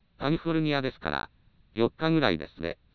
以下に本システムで作成された合成音声を状態継続長の符号化手法別にあげる